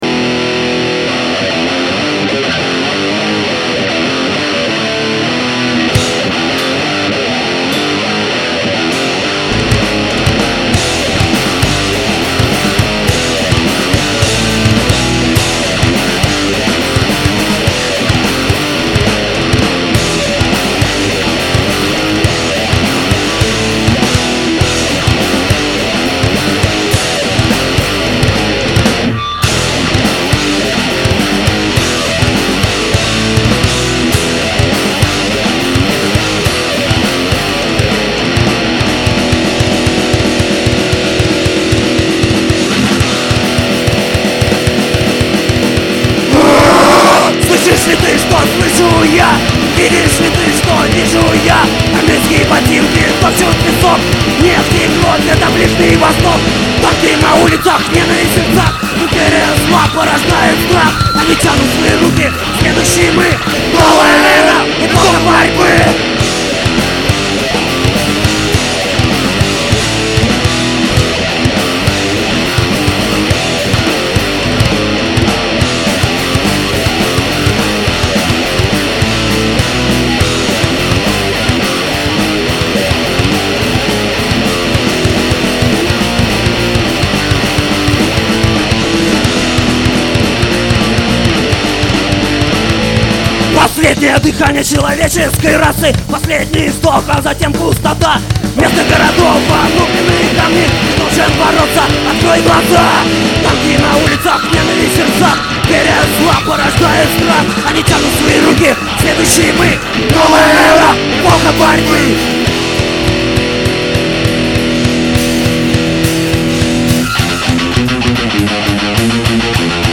Запись одного из первых выступлений ансамбля